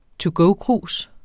to go-krus eller to-go-krus substantiv, intetkøn Bøjning -et, -, -ene Udtale [ toˈgɔw- ] Oprindelse kendt fra 2005 første led engelsk to go egentlig 'til at gå', dvs.